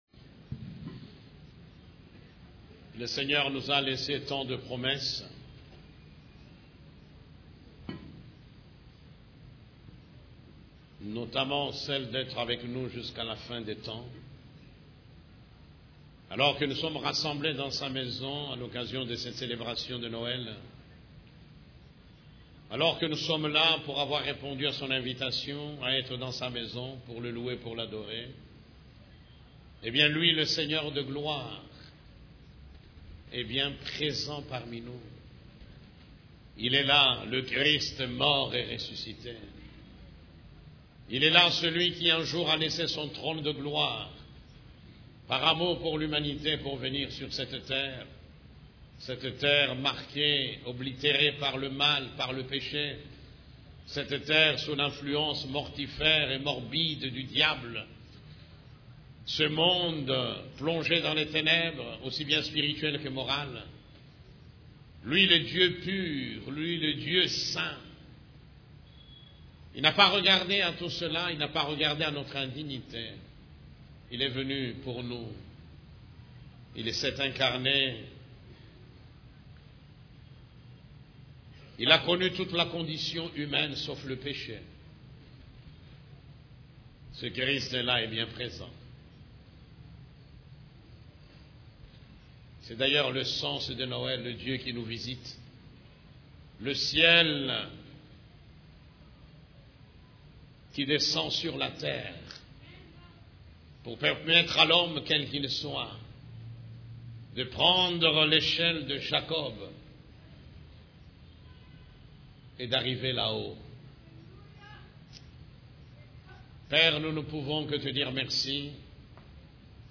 CEF la Borne, Culte du Dimanche, Dieu avec nous et parmi nous